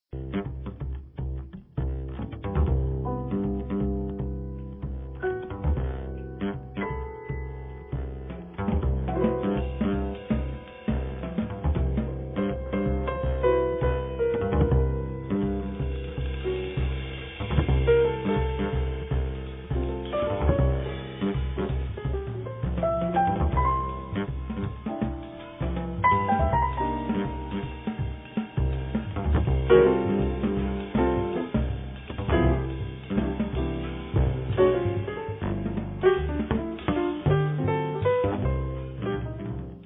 contrabbasso
pianoforte
batteria